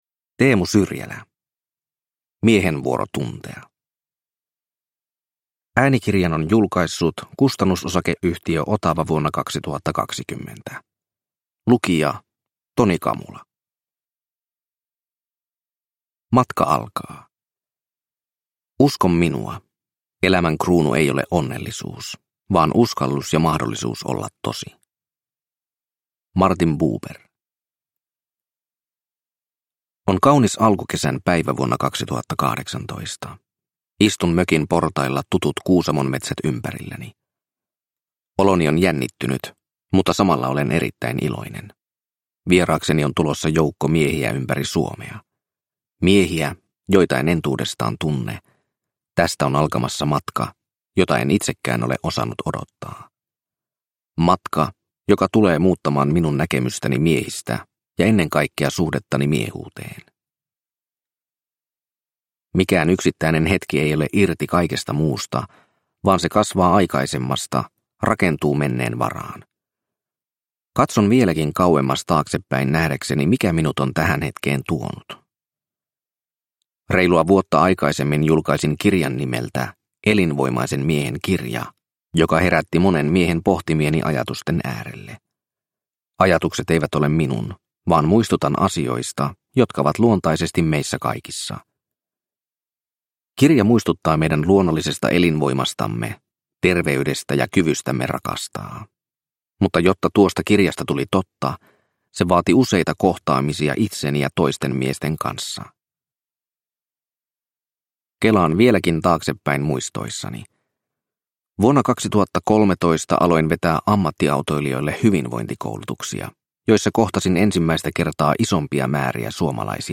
Miehen vuoro tuntea – Ljudbok – Laddas ner